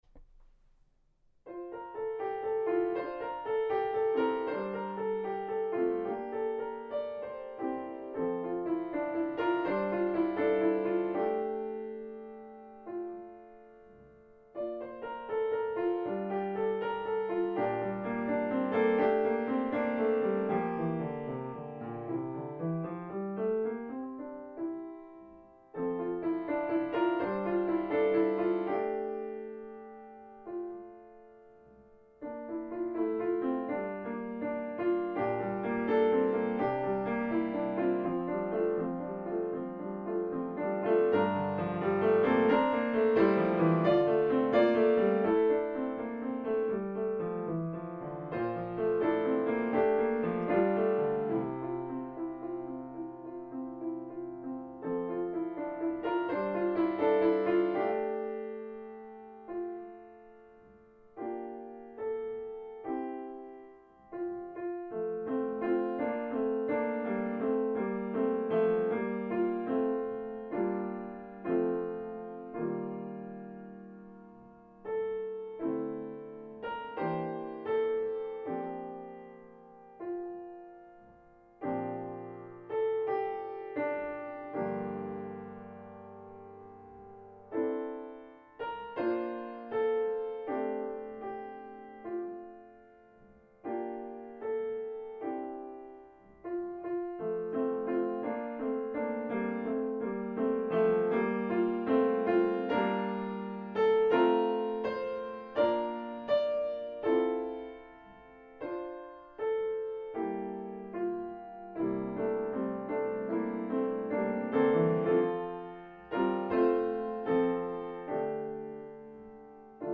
I’m sure it will come as no surprise that I lean towards the melancholy, at least musically, and this one definitely falls into that category.